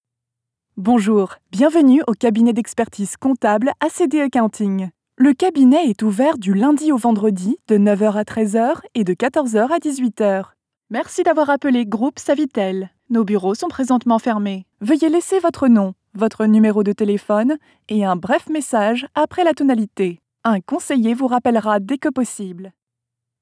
Sprechprobe: Industrie (Muttersprache):
I am a French (Paris) and US-English voice actress.